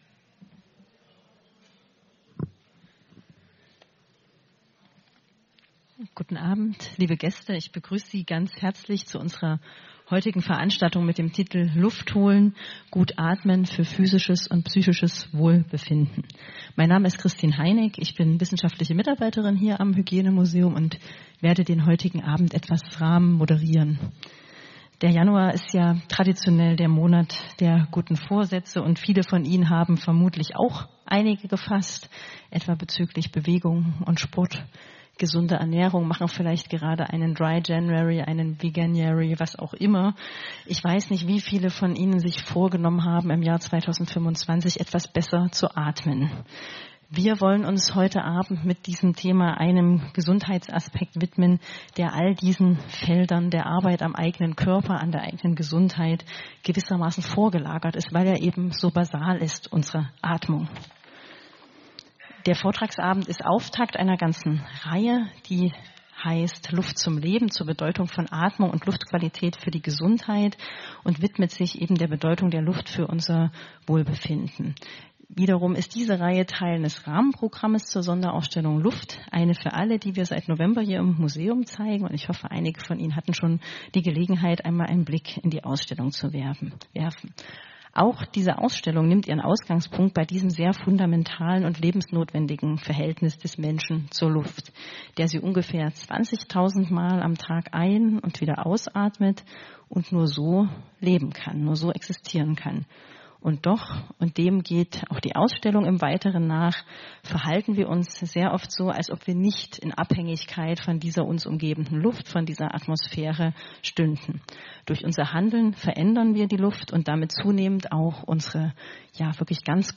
Vortrag_Luft.mp3